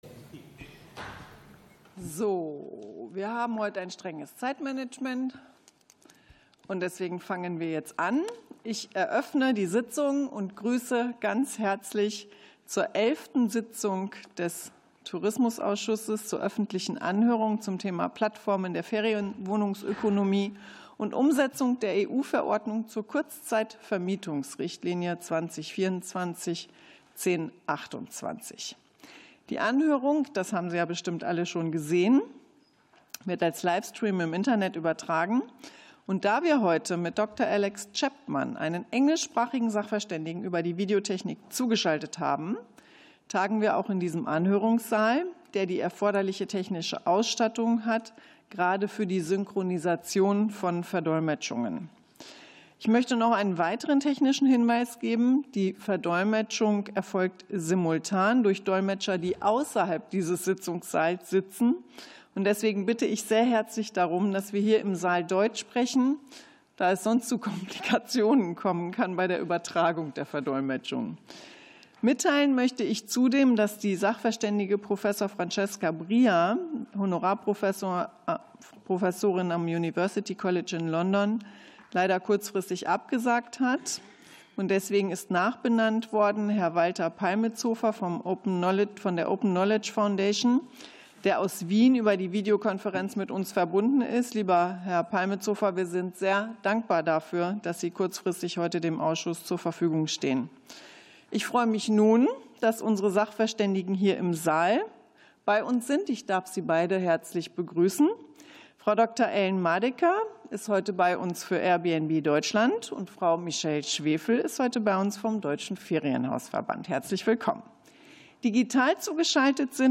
Anhörung des Ausschusses für Tourismus